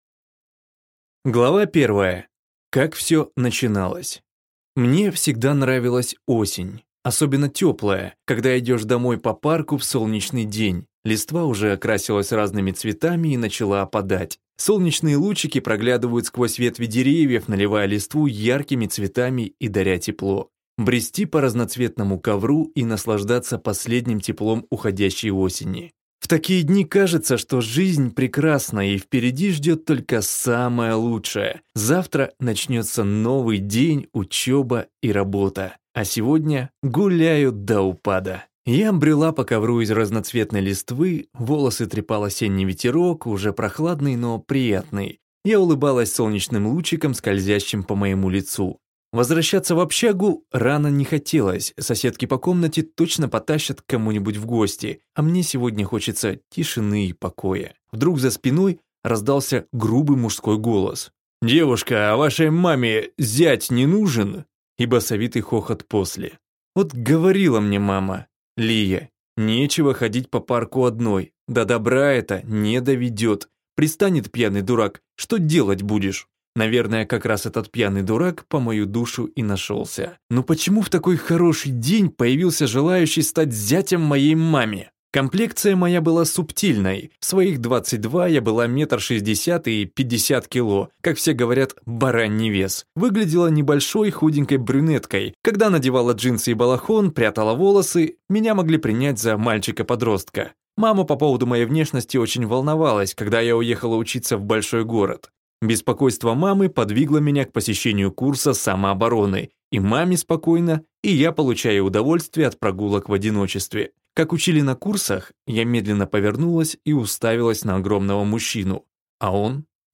Аудиокнига Менеджмент другого мира, или Как попаданке не пропасть | Библиотека аудиокниг